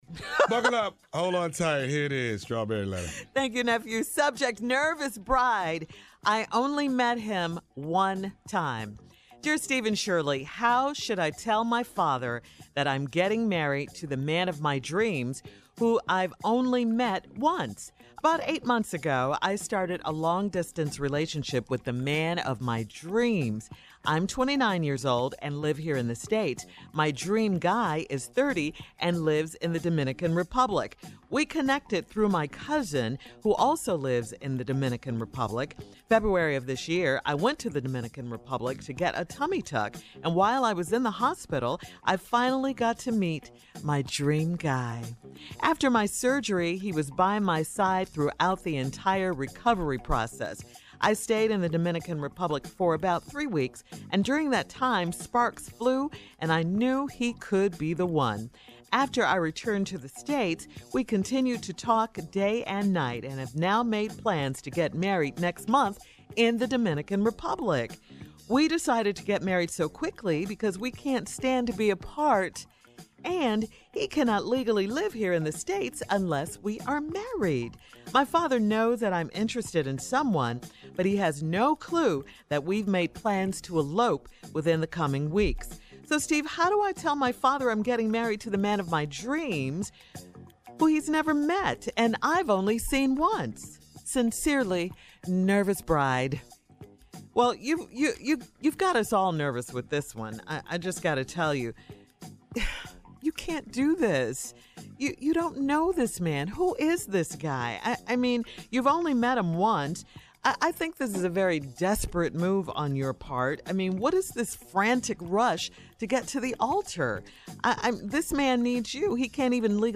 Listen to Steve & Shirley respond to this letter below: